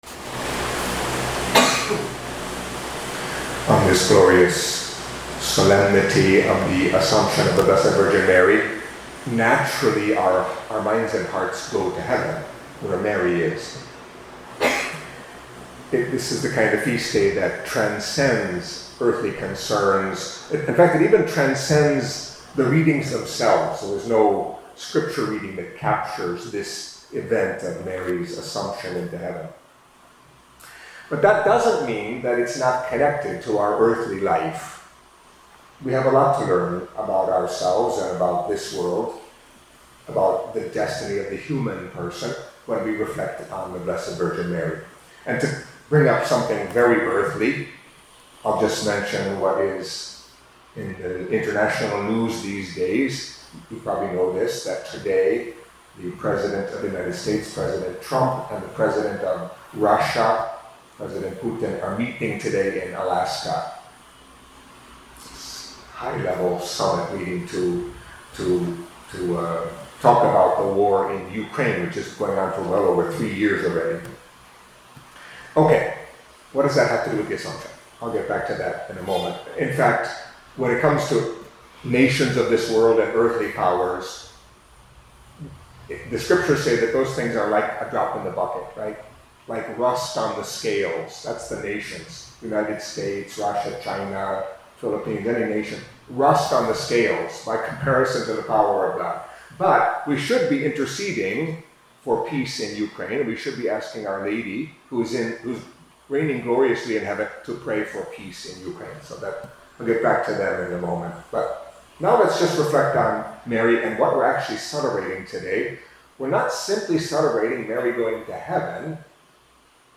Catholic Mass homily for Solemnity of the Assumption of the Blessed Virgin Mary